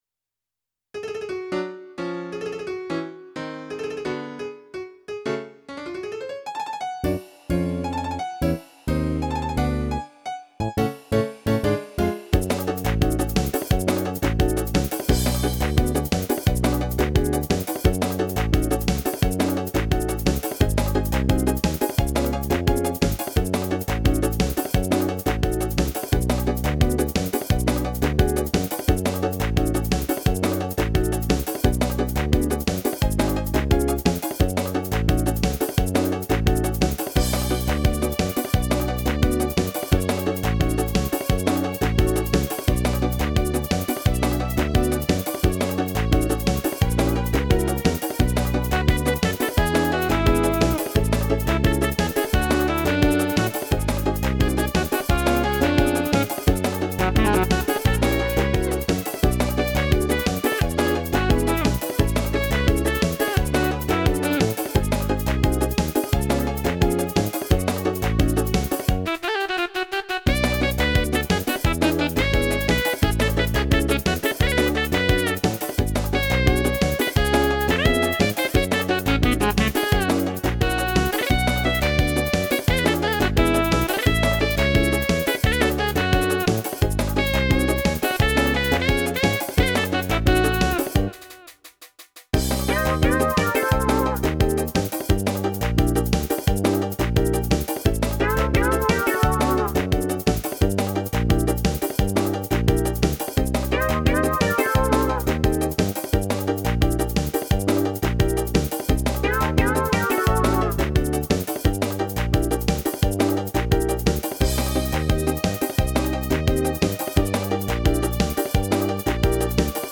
минусовка версия 230120